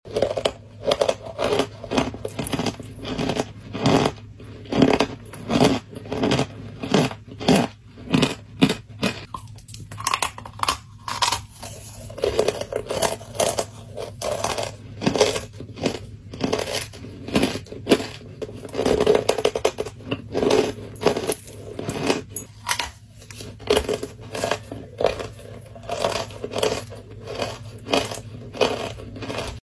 chunks, toasty cereal and crumbles